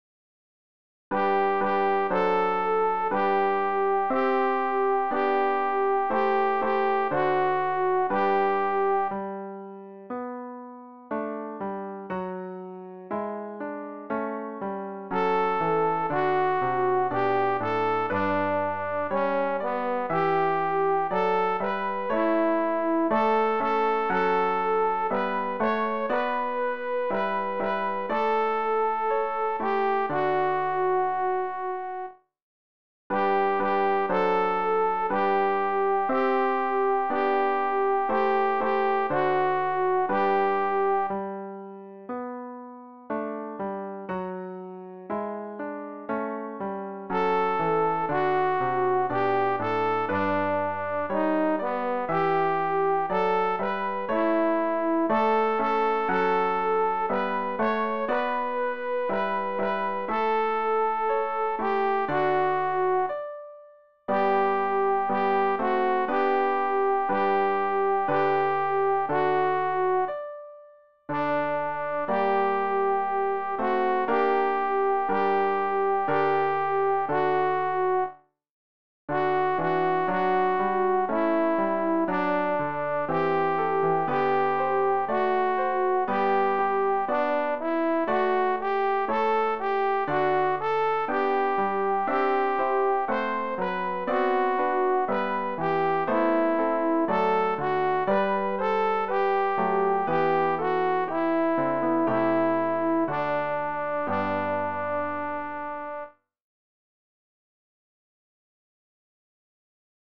alt-anonymus-jubilate-deo.mp3